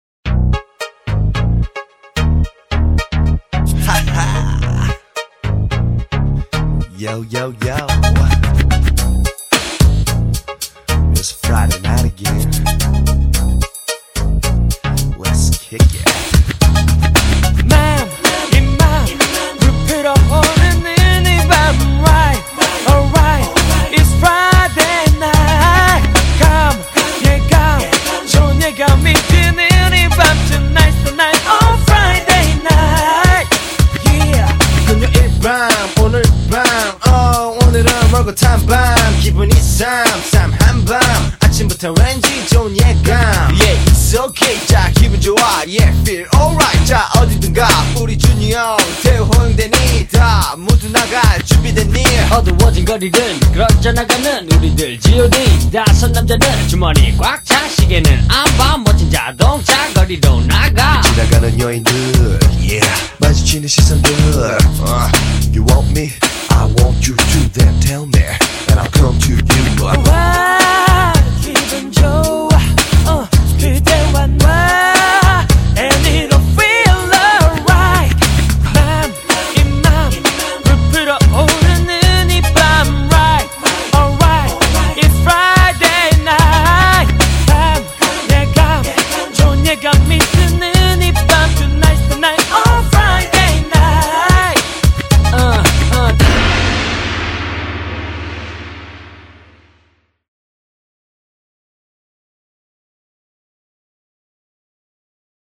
BPM110--1
Audio QualityPerfect (High Quality)